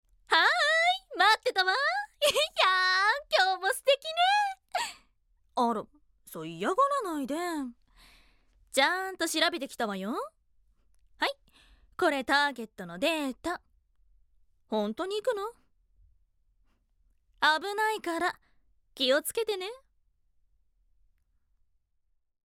ボイスサンプル
セクシーな女性